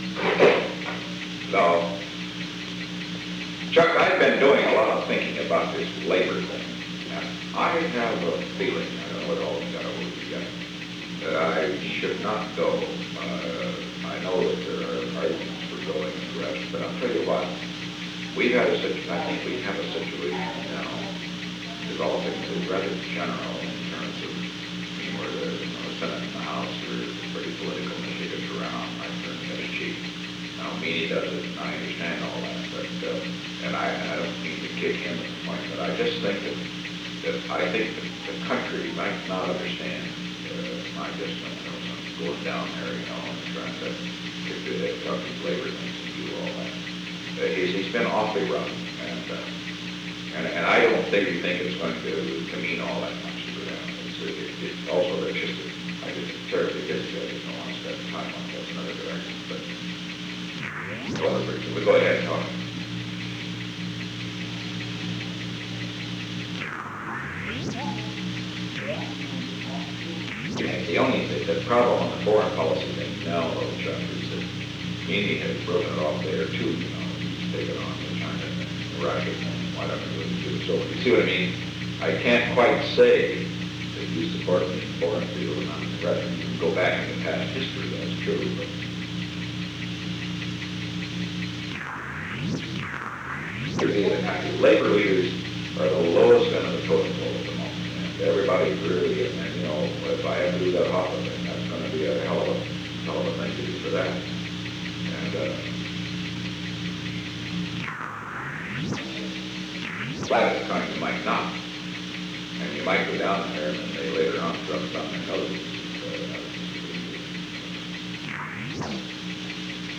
On November 16, 1971, President Richard M. Nixon and Charles W. Colson met in the President's office in the Old Executive Office Building from 3:16 pm to 3:27 pm. The Old Executive Office Building taping system captured this recording, which is known as Conversation 295-012 of the White House Tapes.